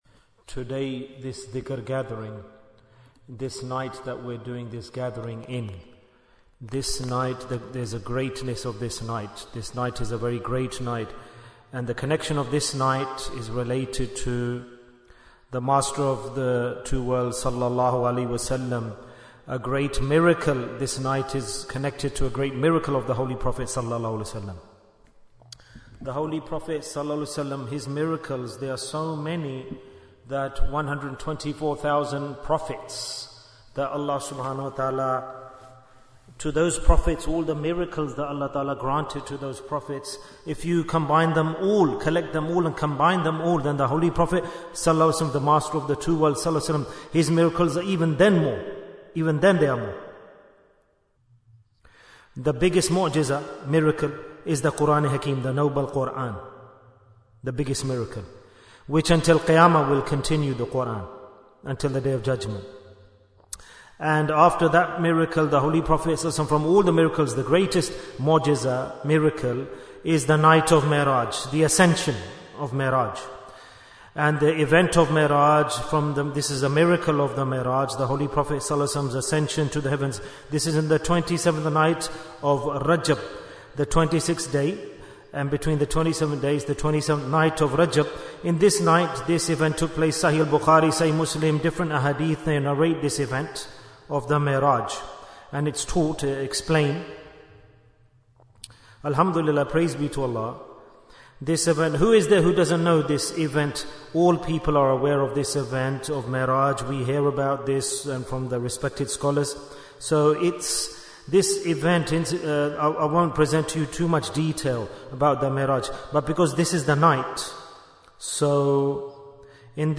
Bayan on Shab-e-Meraj Bayan, 19 minutes26th January, 2025